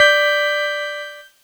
Cheese Chord 25-G4.wav